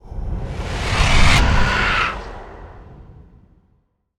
dark_wind_growls_06.wav